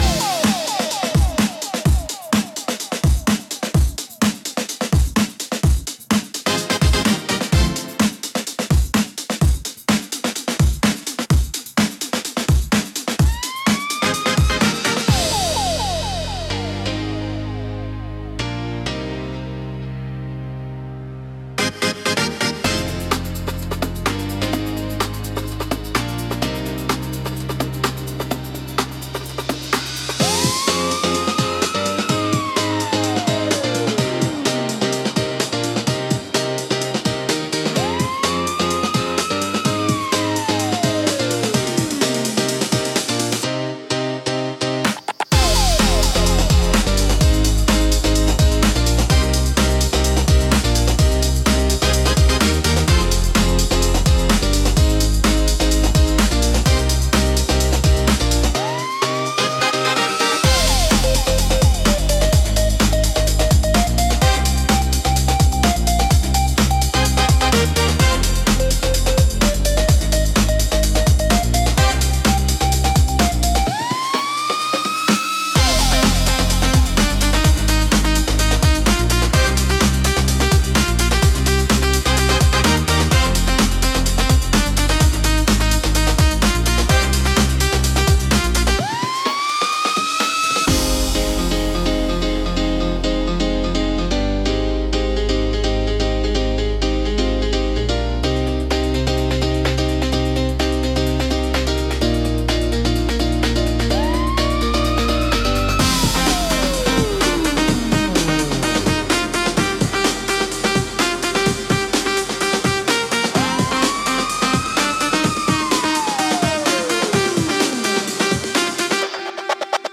躍動感を生み出し、観客やプレイヤーのテンションを上げる効果が高く、飽きさせないペースで強いインパクトを与えます。